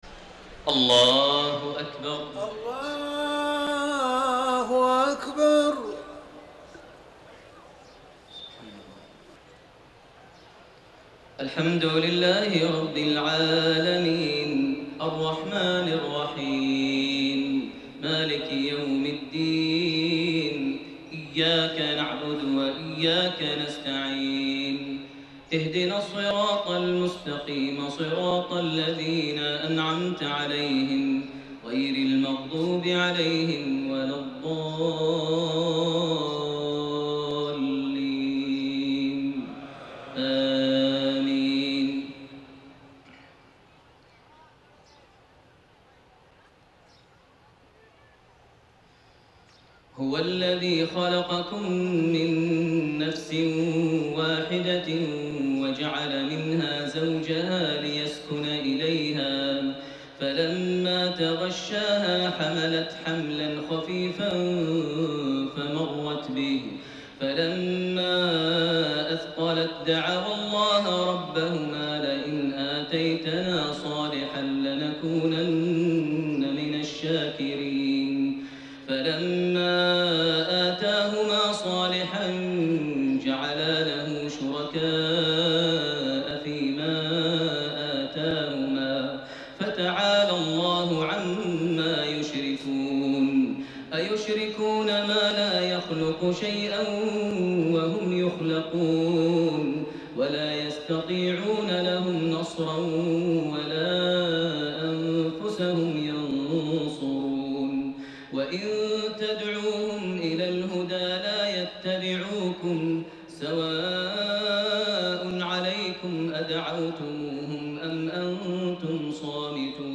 تهجد ليلة 29 رمضان 1430هـ من سورتي الأعراف (189-206) و الأنفال (1-40) Tahajjud 29 st night Ramadan 1430H from Surah Al-A’raf and Al-Anfal > تراويح الحرم المكي عام 1430 🕋 > التراويح - تلاوات الحرمين